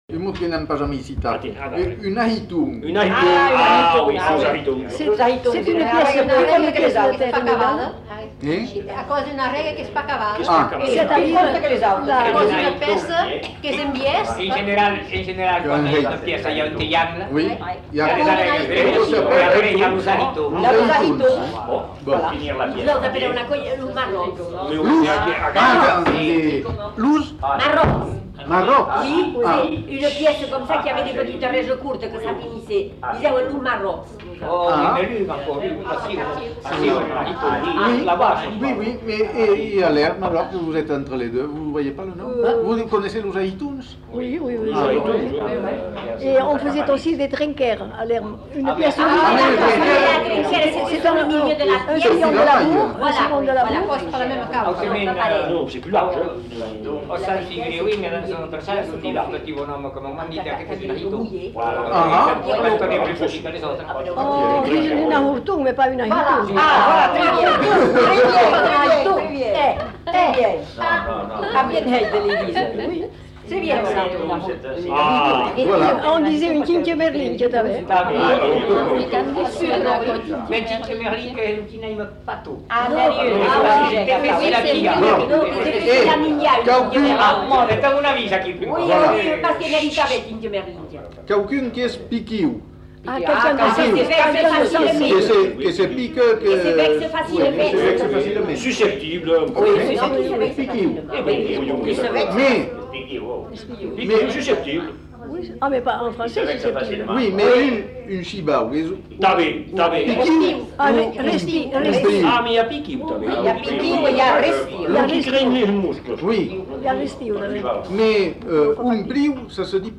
Lieu : Bazas
Genre : témoignage thématique
[enquêtes sonores]